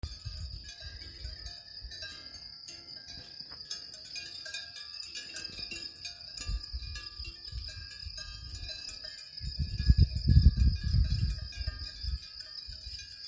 This is a bit of ambient sound recorded during our little walk today. Insects in the meadow, cow bells, a little bit of wind.